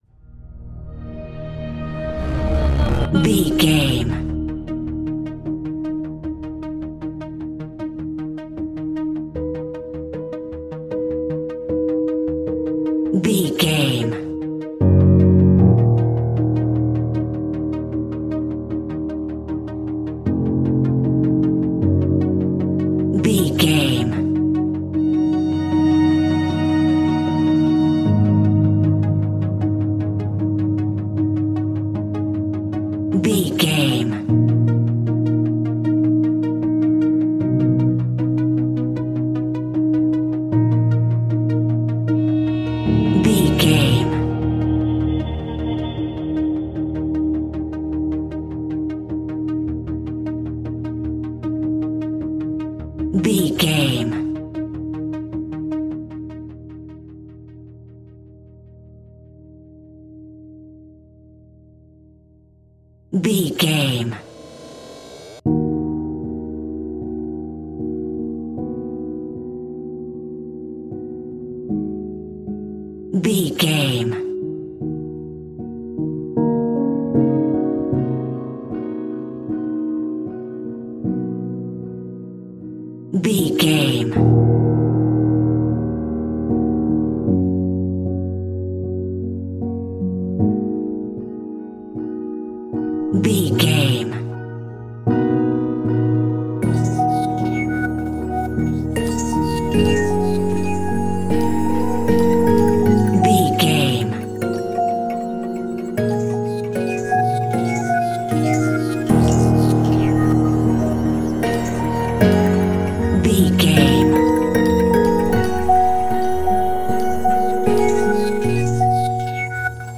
Epic / Action
Fast paced
In-crescendo
Thriller
Ionian/Major
D♯
dark ambient
EBM
drone
synths
Krautrock